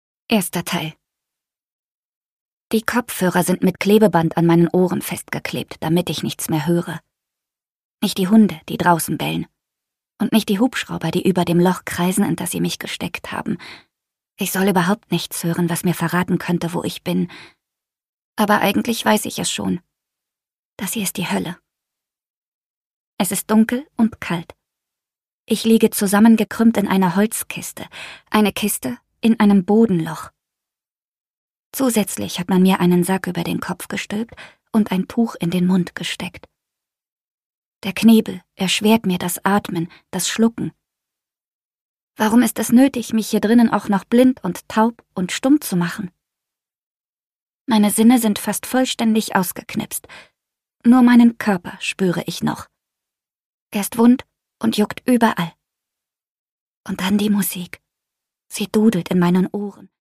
Vera Buck: Der dunkle Sommer (Ungekürzte Lesung)
Produkttyp: Hörbuch-Download